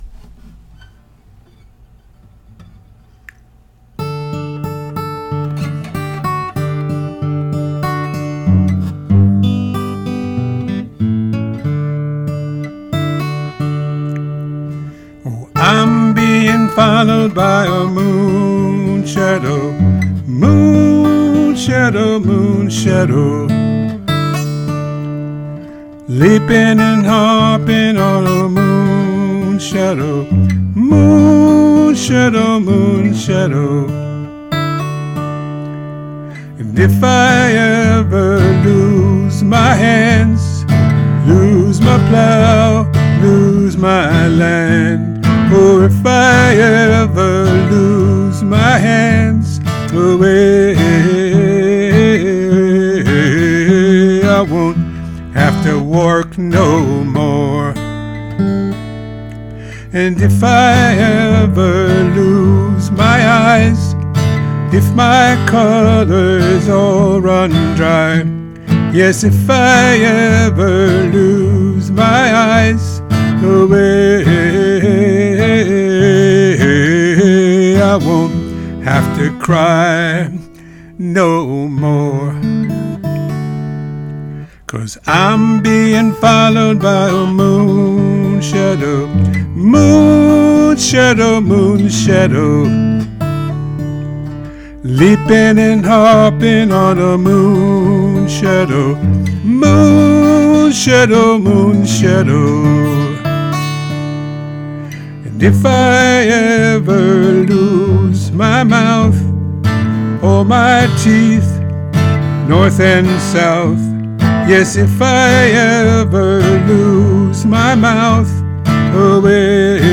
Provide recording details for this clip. Here’s my cover played in the artist’s style.